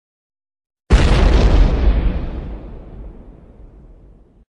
Big - Explosion